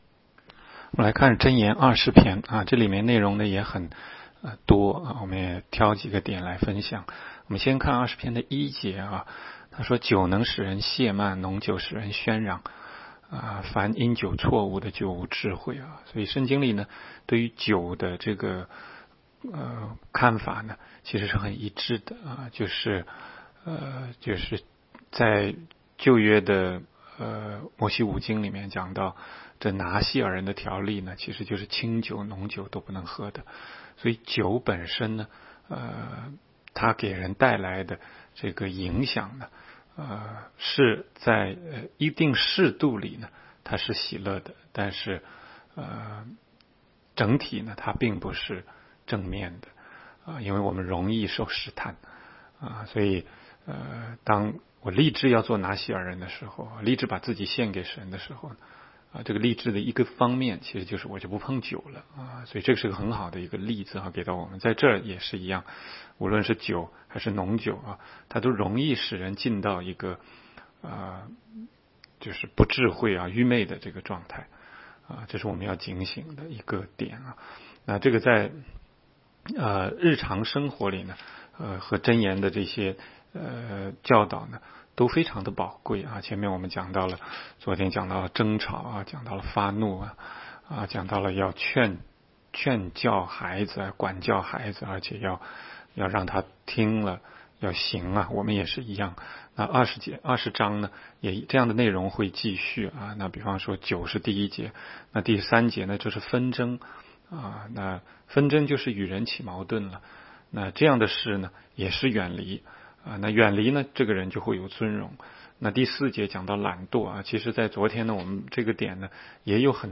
16街讲道录音 - 每日读经 -《 箴言》20章